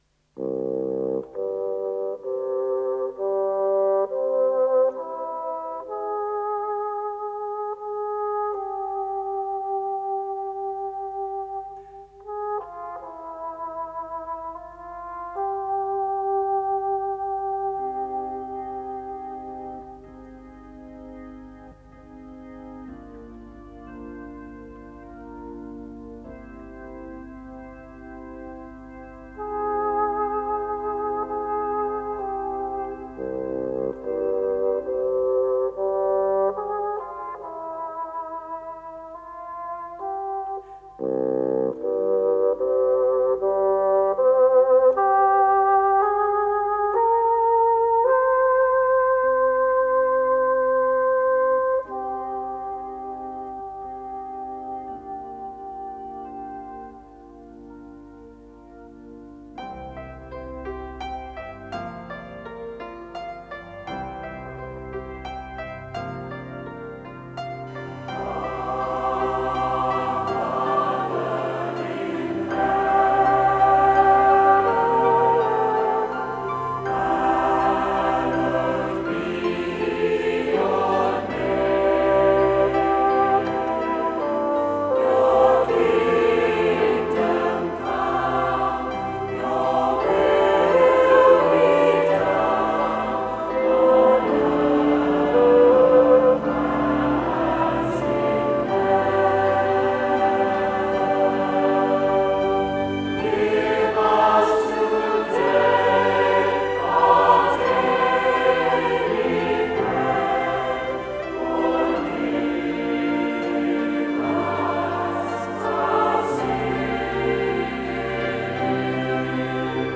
with the Singers and Musicians of Christ Church Clifton